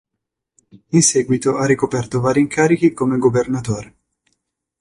Uitgesproken als (IPA)
/ˈva.ri/